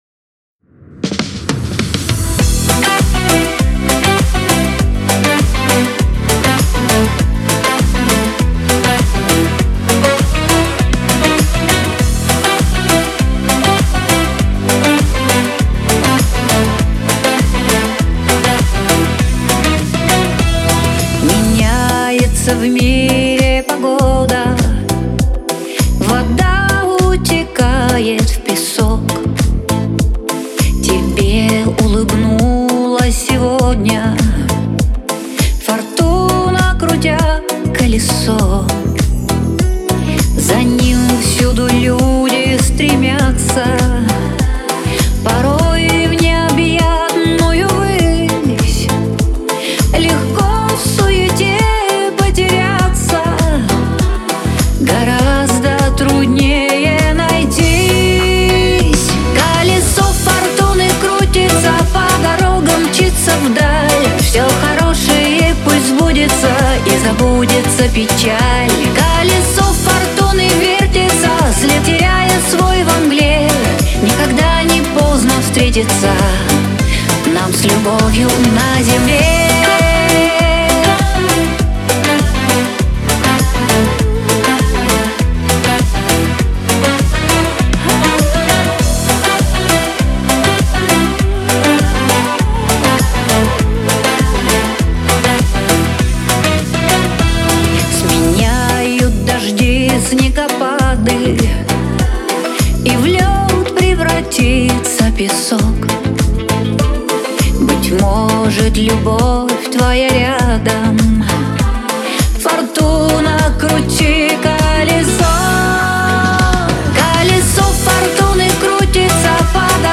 диско
pop , эстрада